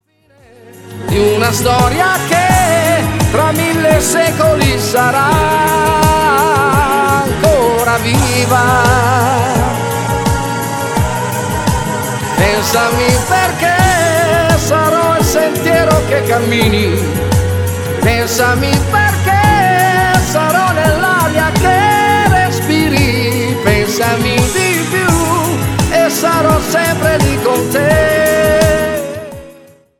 MODERATO CUMBIA  (3.35)